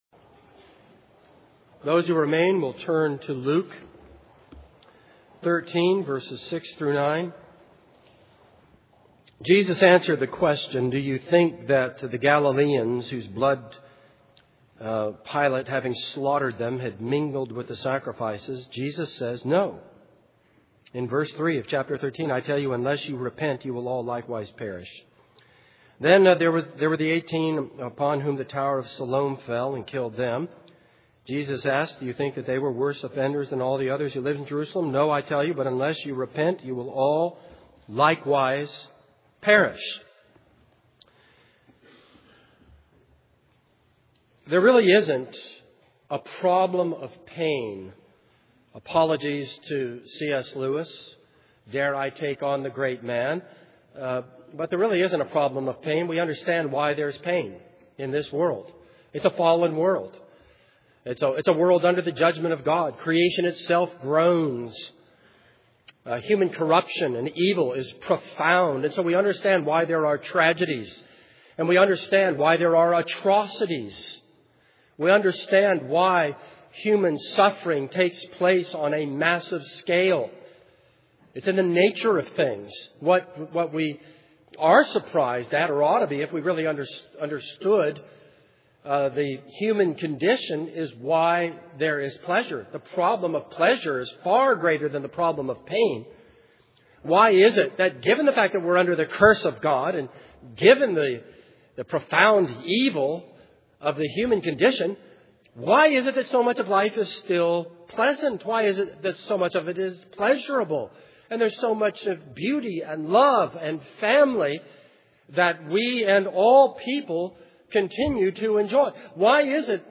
This is a sermon on Luke 13:6-9.